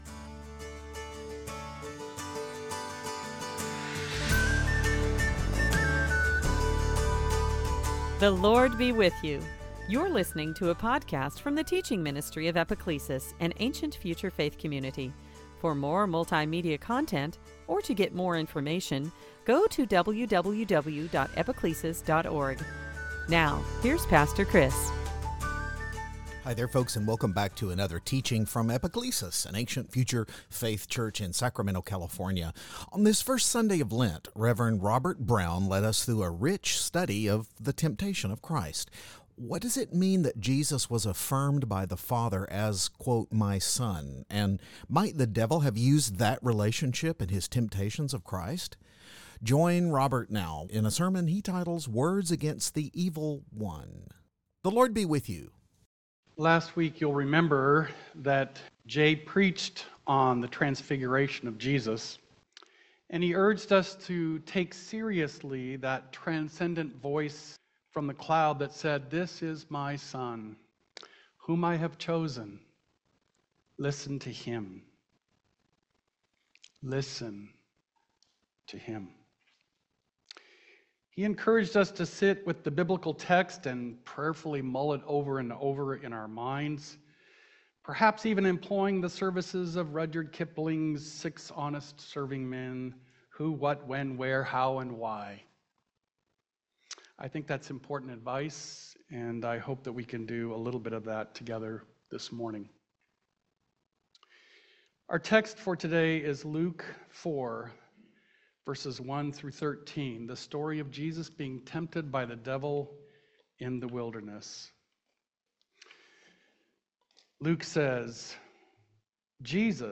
Series: Sunday Teaching
Service Type: Lent